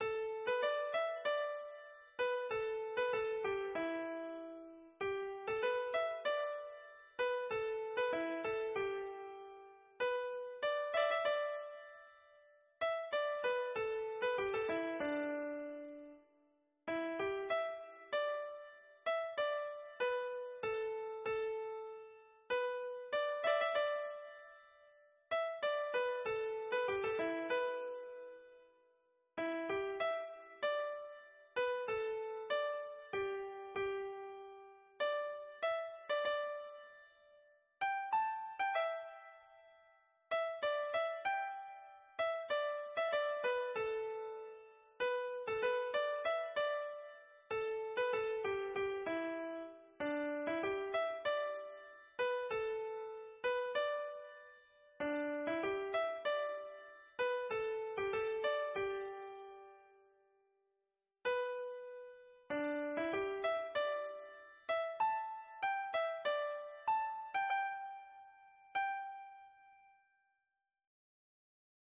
口琴独奏